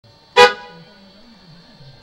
2002年5月28.29日、以前から気になっていた、純正の「プップ〜」音をヨーロピアンな「ファン」音にしたくて、KALXONを購入し取り付けることにしました。
Klaxonホーン
音が全然違います。かなり大きな音になりました。
klax.mp3